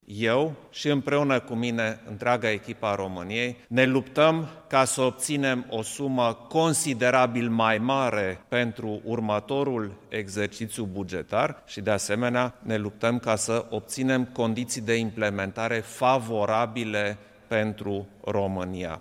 Preşedintele Klaus Iohannis a declarat, la finalul reuniunii Consiliului European desfăşurată, ieri, în sistem videoconferinţă, că Romania doreşte să obţină o sumă mai mare în viitorul exerciţiu financiar al Uniunii, în condiţiile în care, în actualul buget, ţara noastră a avut dreptul la 44 de miliarde de euro: